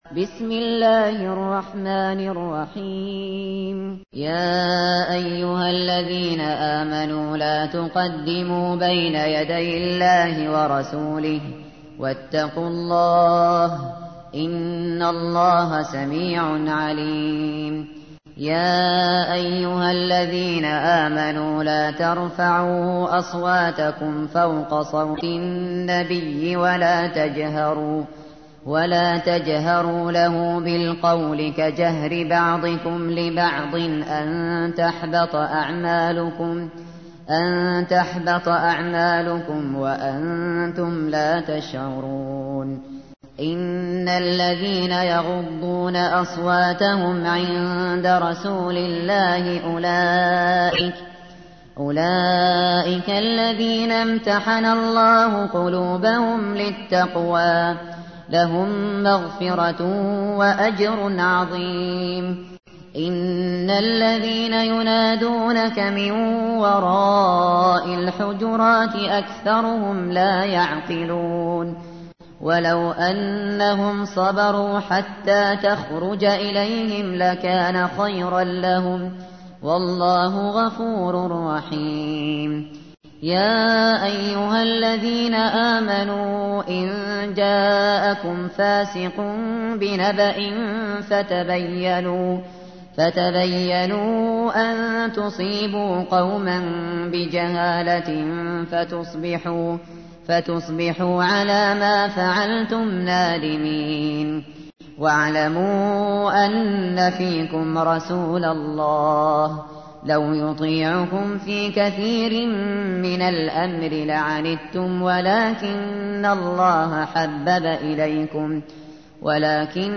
تحميل : 49. سورة الحجرات / القارئ الشاطري / القرآن الكريم / موقع يا حسين